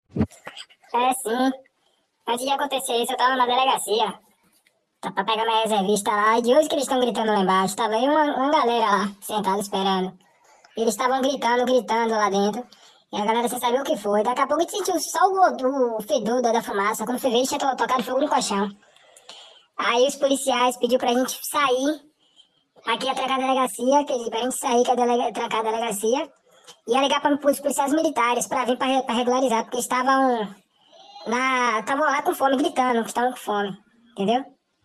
Presos de São Francisco do Conde iniciaram agora há pouco, uma batucada e gritaria na 21ª DT de São Francisco do Conde. Segundo uma testemunha, eles tocaram fogo em colchões e pediam comida.